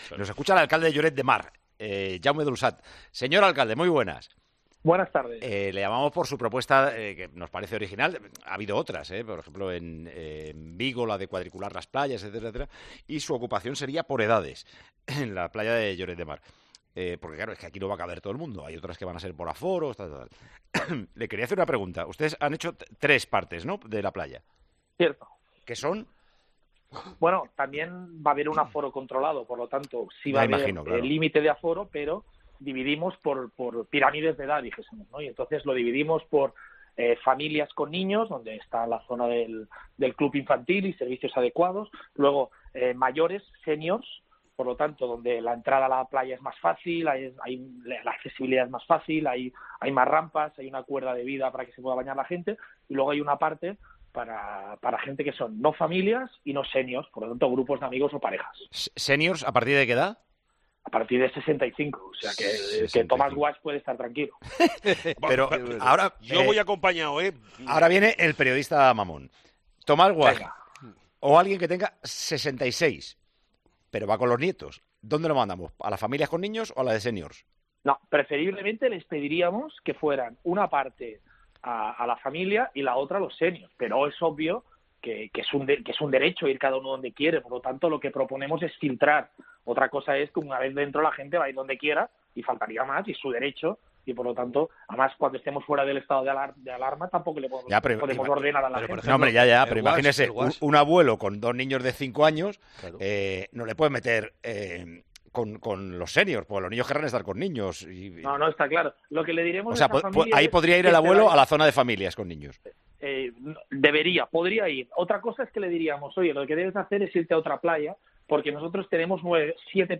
El alcalde de Lloret de Mar explica en Tiempo de Juego su propuesta para ir a la playa este verano
Con Paco González, Manolo Lama y Juanma Castaño